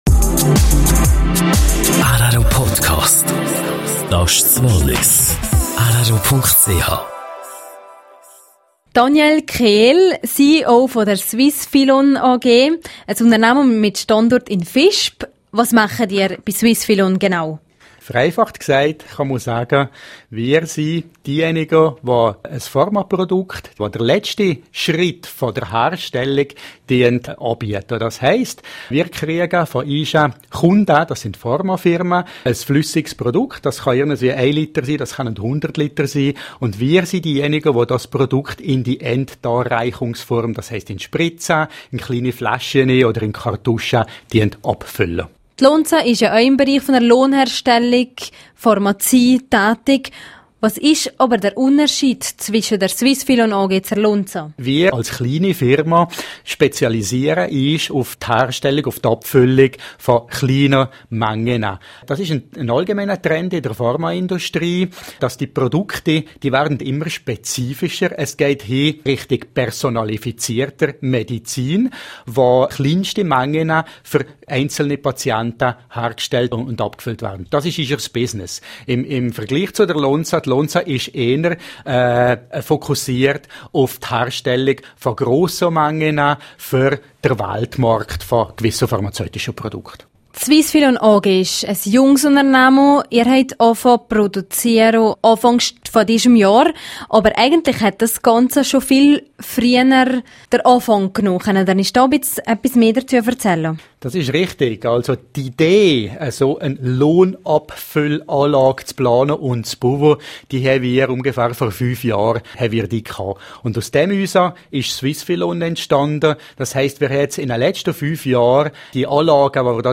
rro-Interview